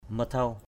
mathau.mp3